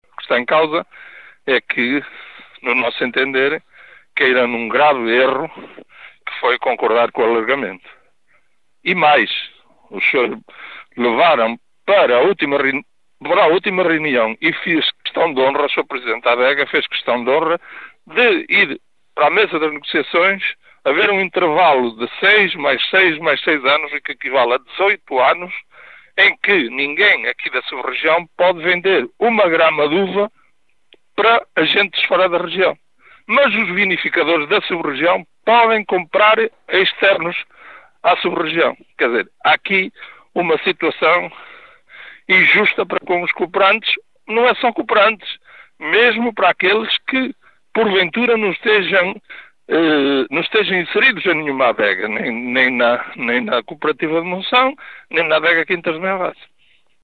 um dos viticultores